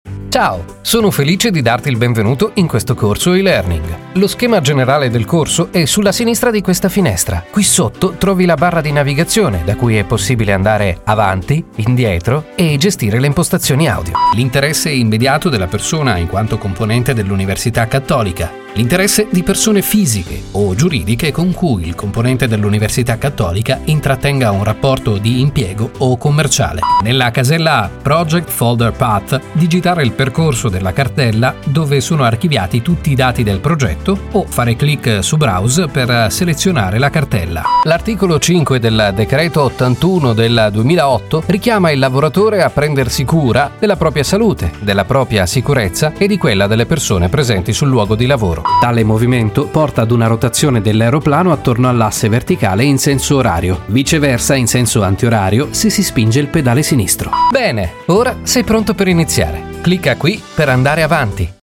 A versatile and multipurpose voice, suitable for any production. Extremely flexible native italian voice.
Sprechprobe: eLearning (Muttersprache):